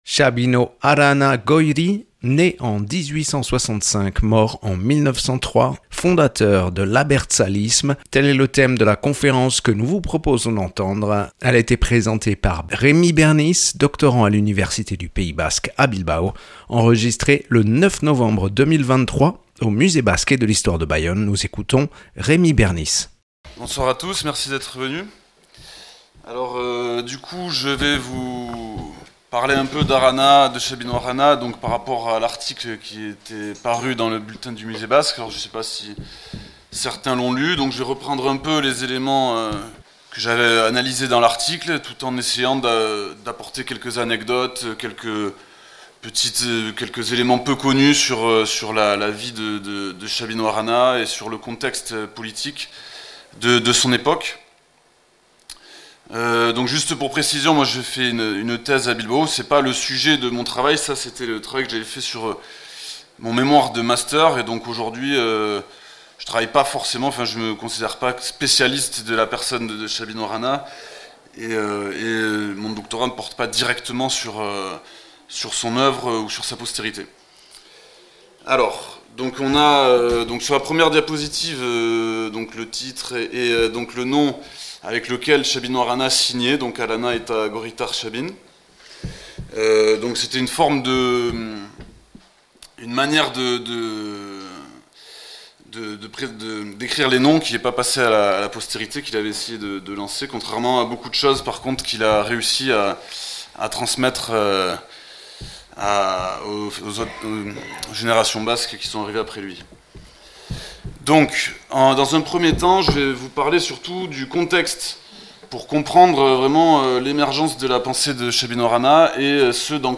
(Enregistrée le 09/11/2023 au Musée Basque et de l’histoire de Bayonne).